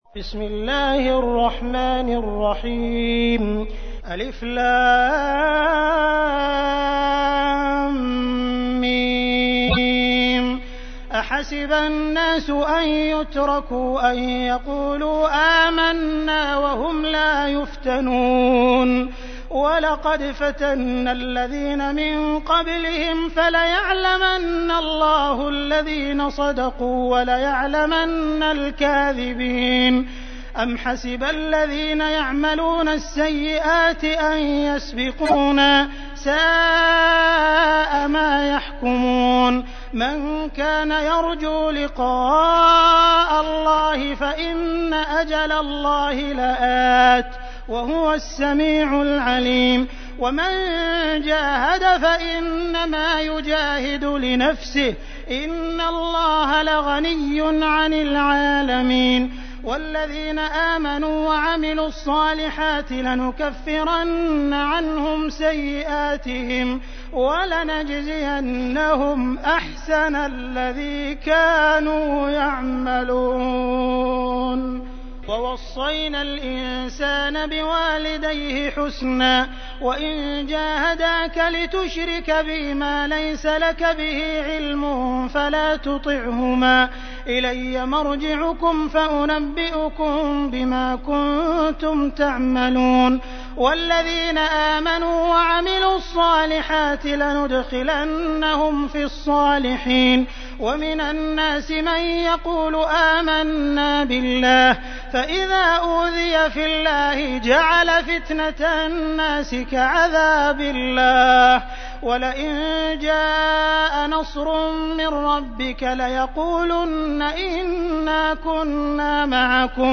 تحميل : 29. سورة العنكبوت / القارئ عبد الرحمن السديس / القرآن الكريم / موقع يا حسين